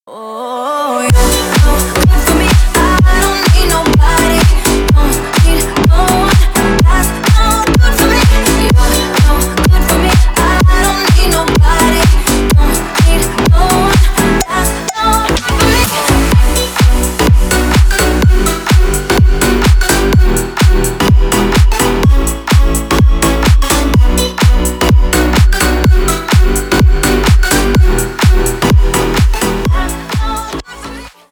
Ремикс
Танцевальные
громкие